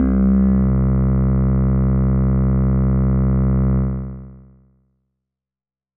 Organesq Pad C2.wav